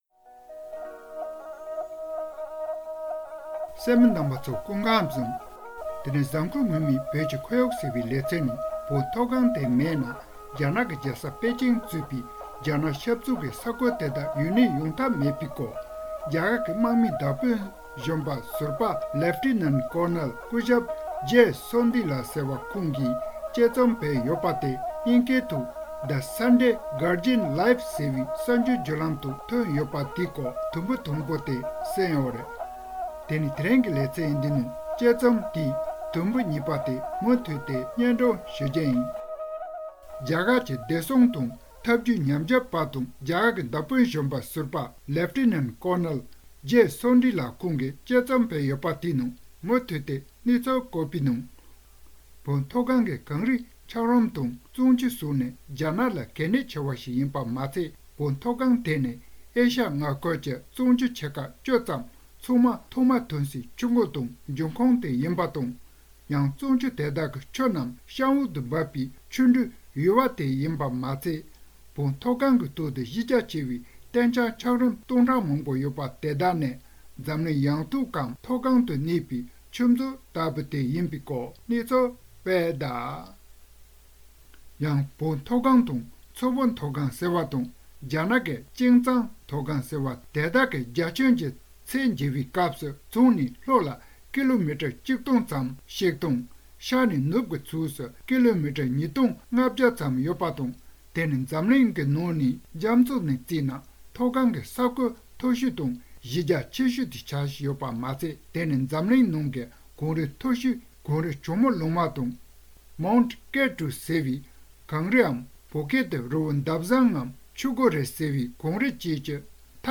དུམ་བུ་གཉིས་པ། སྒྲ་ལྡན་གསར་འགྱུར།